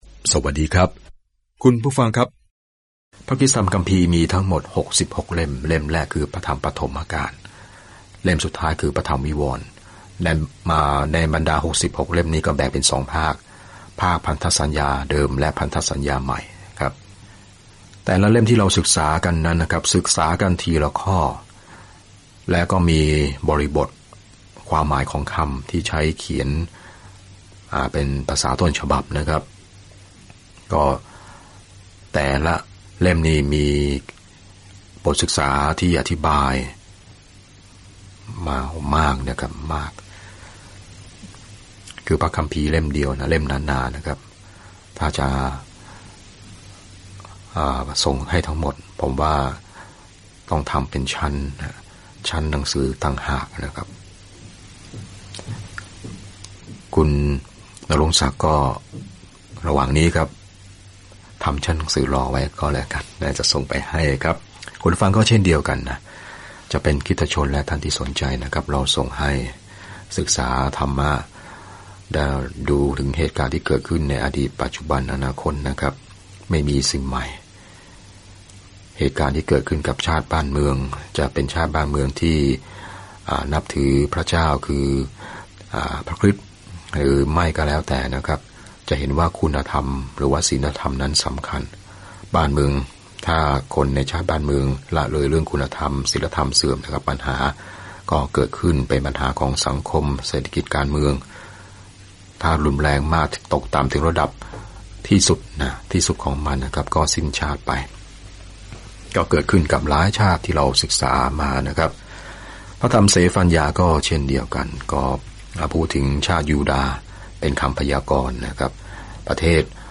เศฟันยาห์เตือนอิสราเอลว่าพระเจ้าจะพิพากษาพวกเขา แต่บอกพวกเขาด้วยว่าพระองค์ทรงรักพวกเขามากเพียงใด และวันหนึ่งพระองค์จะทรงชื่นชมยินดีเหนือพวกเขาด้วยการร้องเพลงอย่างไร เดินทางทุกวันผ่านเศฟันยาห์ในขณะที่คุณฟังการศึกษาด้วยเสียงและอ่านข้อที่เลือกจากพระวจนะของพระเจ้า